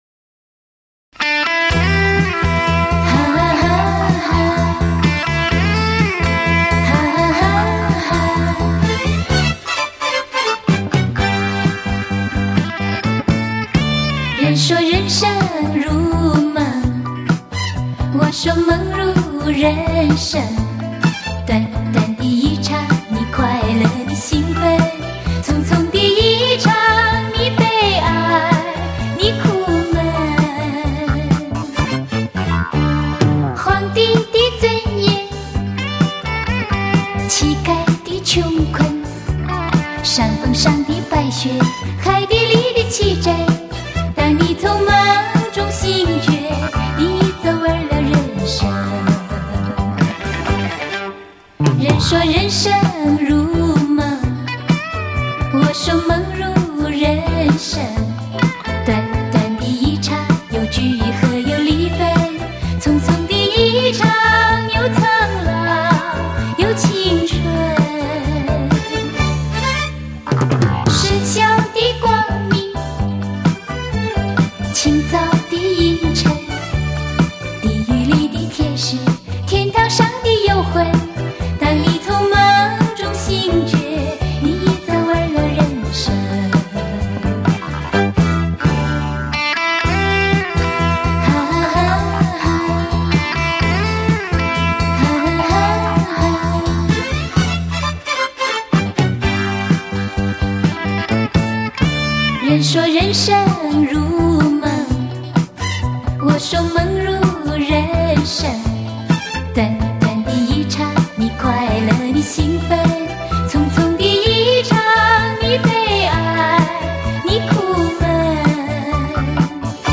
发音吐字清晰、准确，以及对歌曲诠释的理解和把握都十分到位。